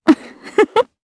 Ripine-Vox_Happy4_jp.wav